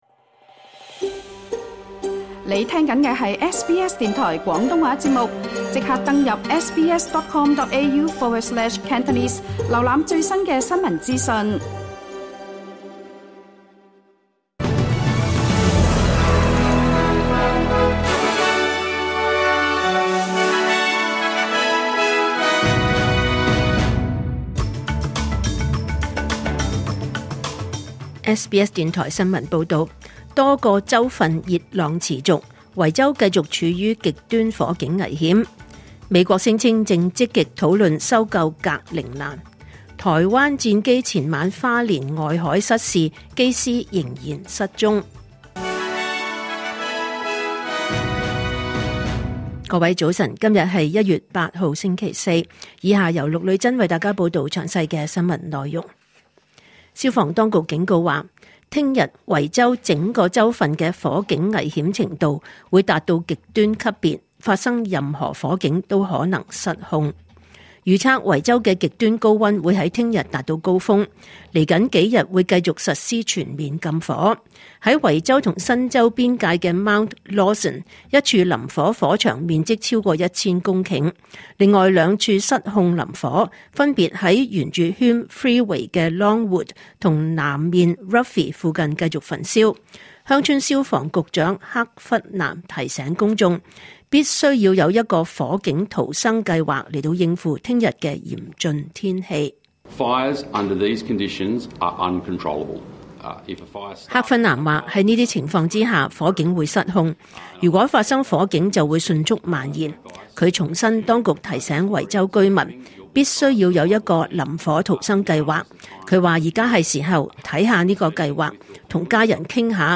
2026 年 1 月 8 日 SBS 廣東話節目九點半新聞報道。